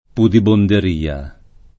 pudibonderia [ pudibonder & a ] s. f.